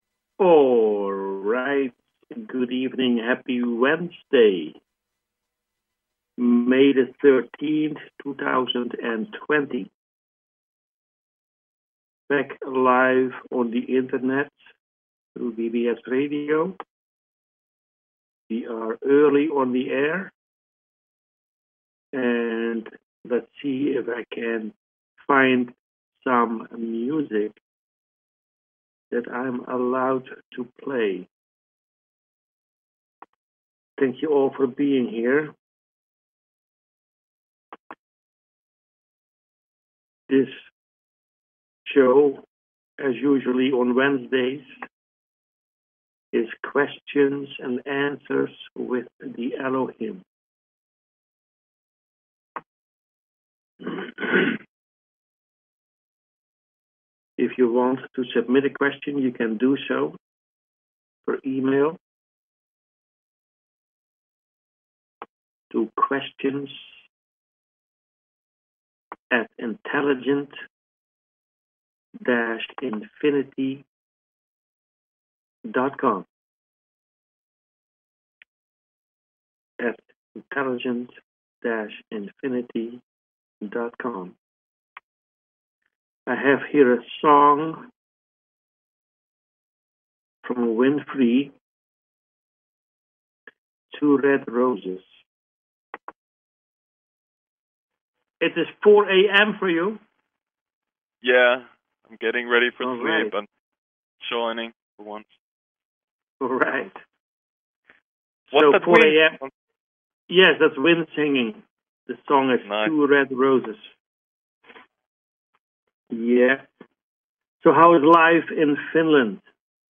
Talk Show Episode
Talk Show